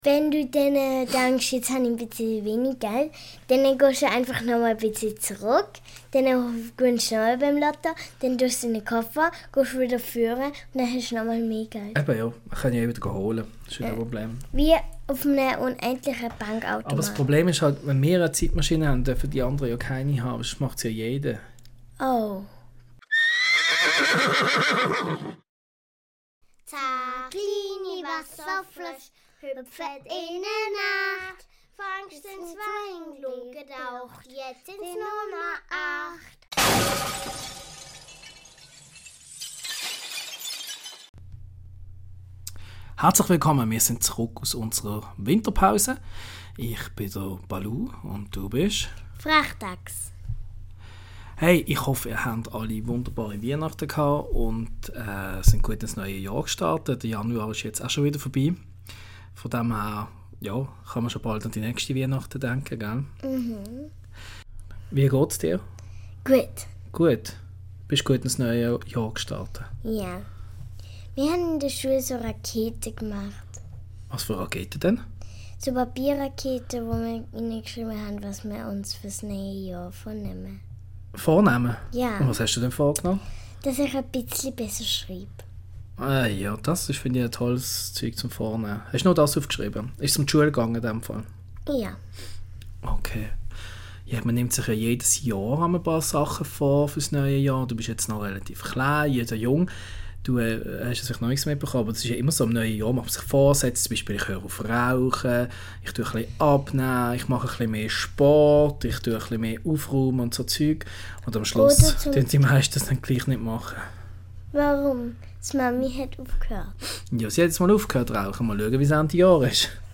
Ein Vater Töchter Podcast